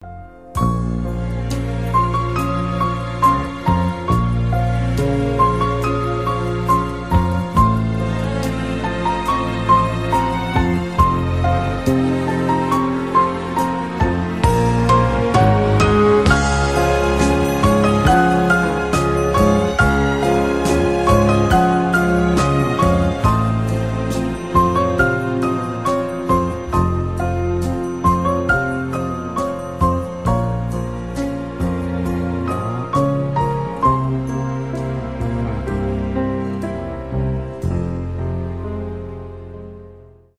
романтические
инструментальные
без слов